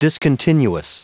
Prononciation du mot discontinuous en anglais (fichier audio)
Prononciation du mot : discontinuous